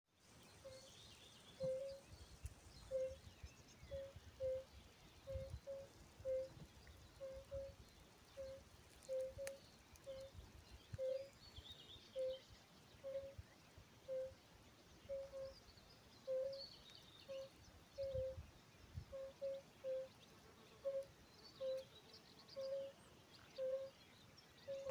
Sarkanvēdera ugunskrupis, Bombina bombina
Skaits8
StatussDzied ligzdošanai piemērotā biotopā (D)